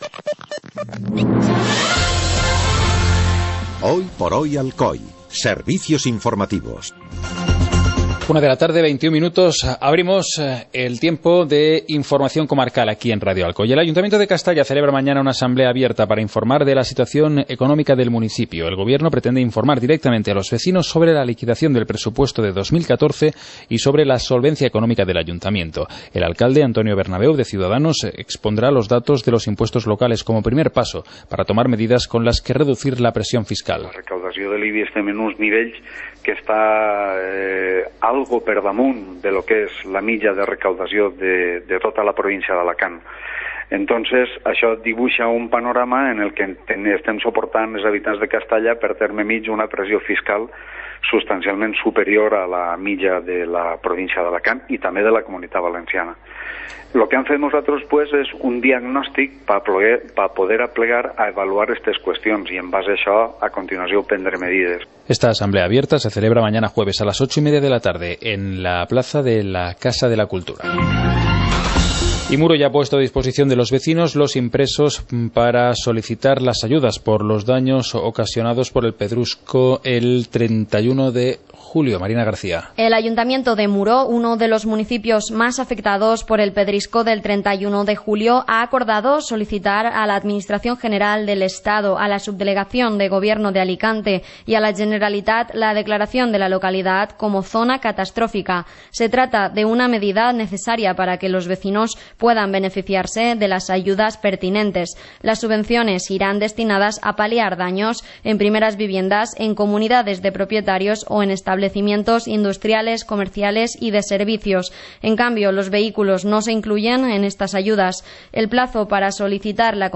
Informativo comarcal - miércoles, 26 de agosto de 2015